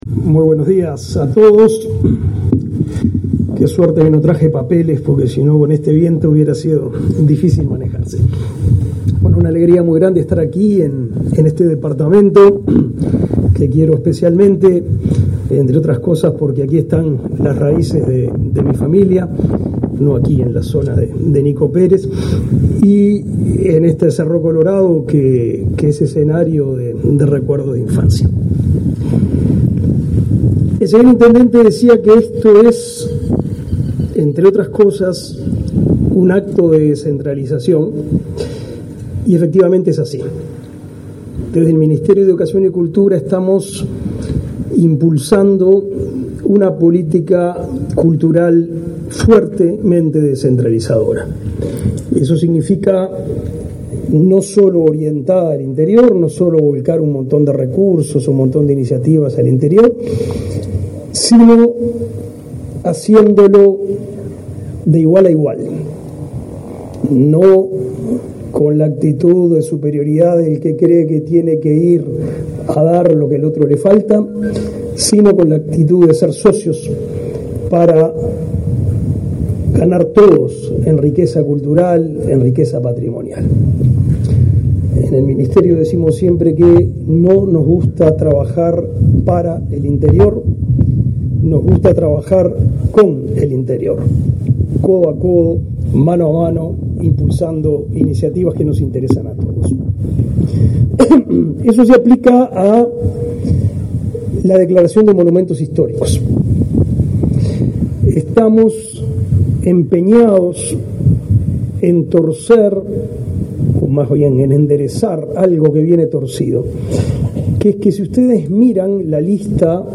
Conferencia de prensa del MEC por declaración como Monumento Histórico Nacional de construcciones en Alejandro Gallinal
Conferencia de prensa del MEC por declaración como Monumento Histórico Nacional de construcciones en Alejandro Gallinal 24/10/2022 Compartir Facebook X Copiar enlace WhatsApp LinkedIn El Ministerio de Educación y Cultura (MEC) declaró Monumento Histórico Nacional al conjunto edilicio integrado por El Carrillón, el tanque de OSE y el anfiteatro Víctor Damiani de la localidad Dr. Alejandro Gallinal, del departamento de Florida. Participaron en el evento, realizado el 24 de octubre, el ministro del MEC, Pablo da Silveira, y el director general de la Comisión del Patrimonio Cultural de la Nación, William Rey.